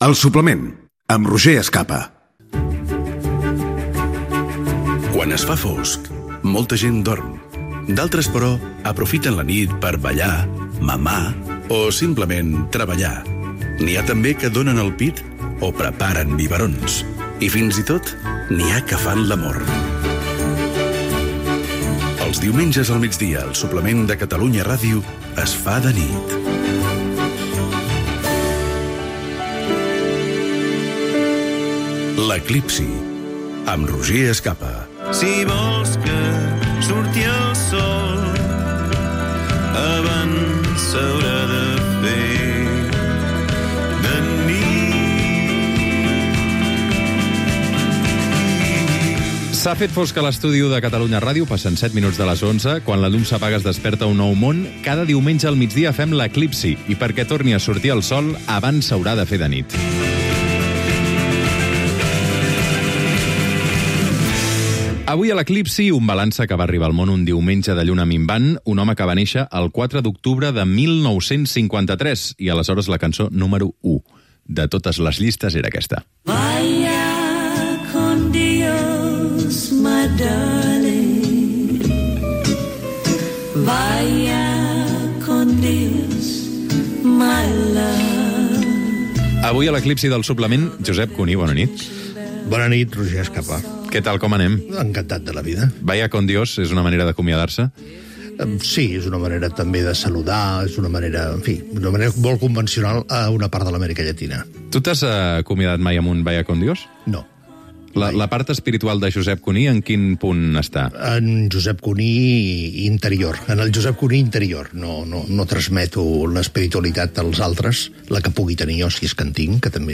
Indicatiu del programa, secció "L'eclipsi". Convidat: Josep Cuní.
Entreteniment